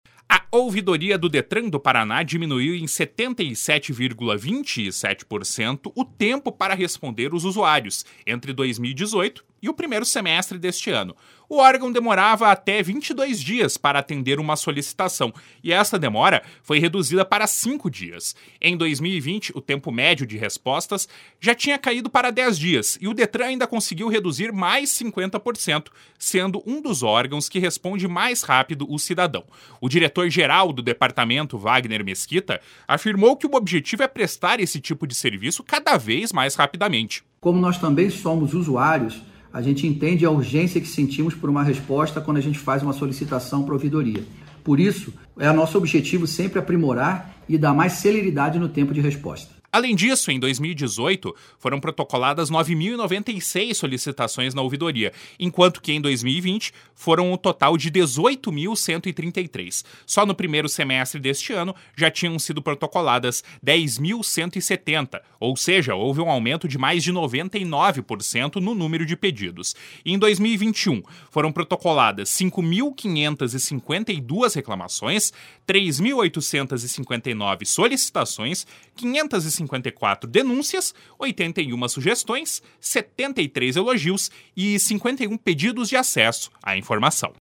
O diretor-geral do Departamento, Wagner Mesquita, afirmou que o objetivo é prestar esse tipo de serviço cada vez mais rapidamente. // SONORA WAGNER MESQUITA //